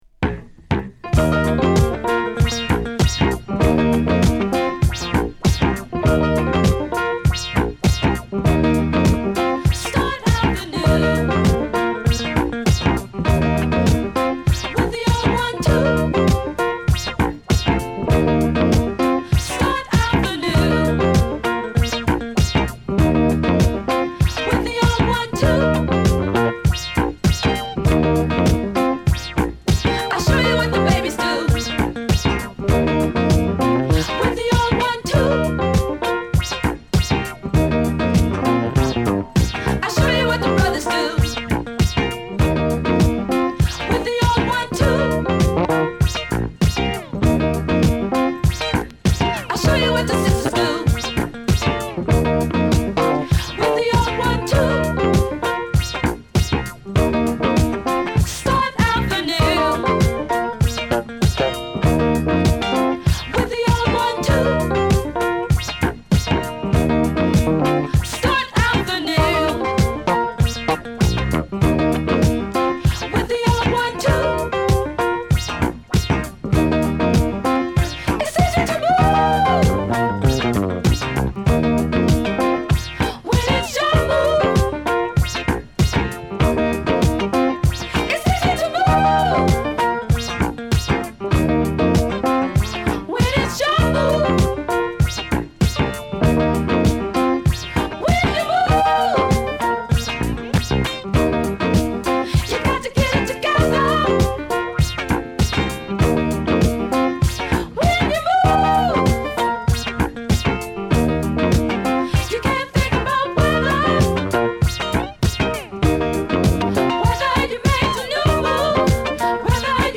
軽やかな伴奏や全編に渡って女性ヴォーカルがセクシーな演奏者達と絡み合うグッドチューン！！サイドBもバツグンの高揚感！！